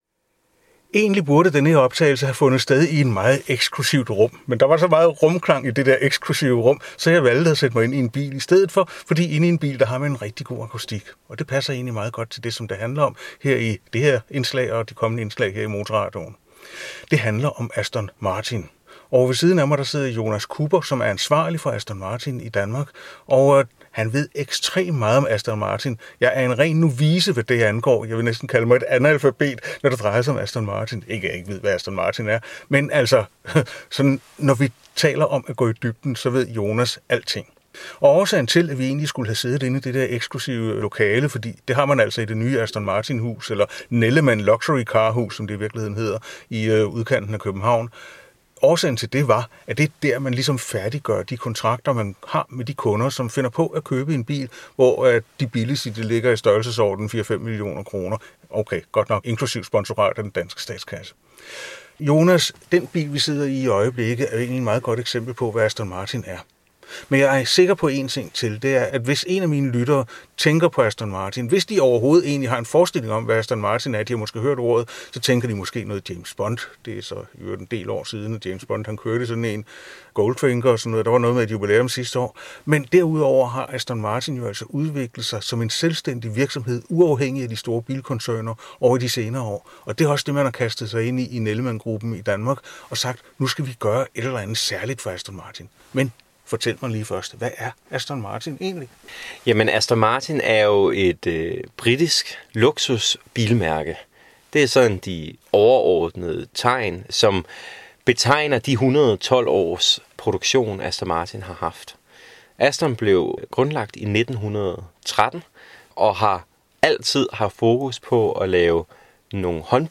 Det er helt i overensstemmelse med fabrkkens storsatsning, som ogs� omfatter Le Mans og Formel 1. Samtalen finder sted i Nellemann Luxury Cars nye domicil udenfor K�benhavn.